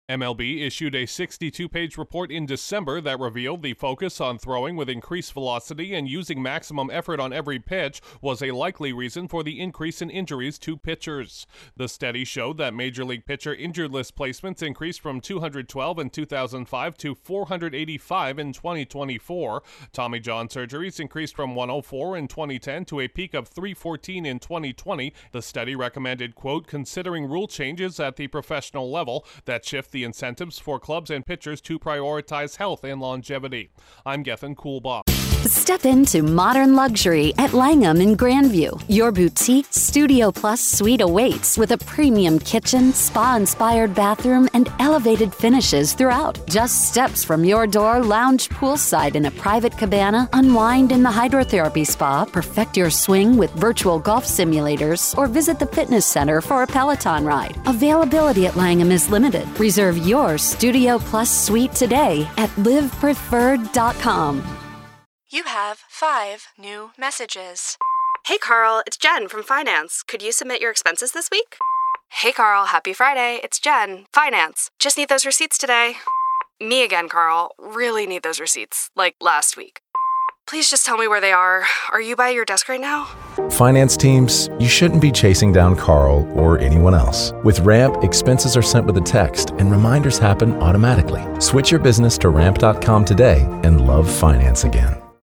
An increase in injuries could lead to baseball limiting its flamethrowers. Correspondent